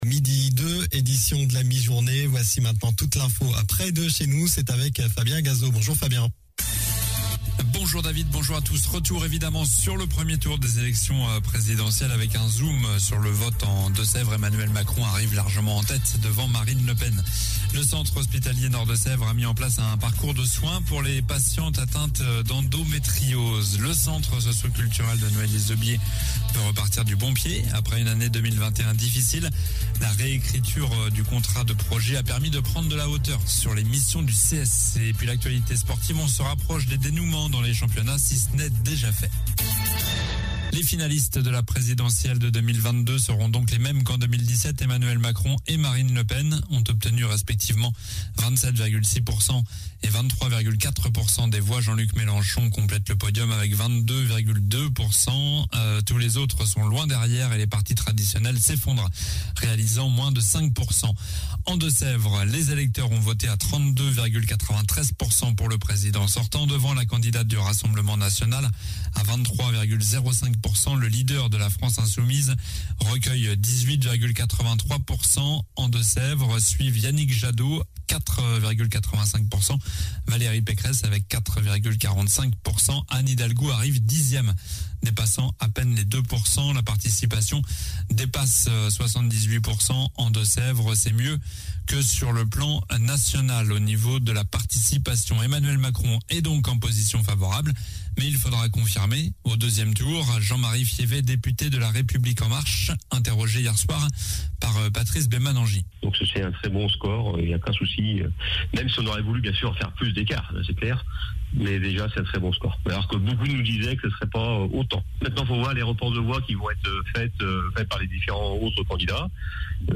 Journal du lundi 11 avril (midi)